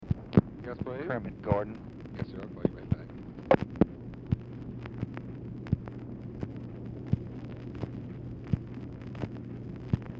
Telephone conversation # 6624, sound recording, LBJ and SIGNAL CORPS OPERATOR, 12/31/1964, time unknown | Discover LBJ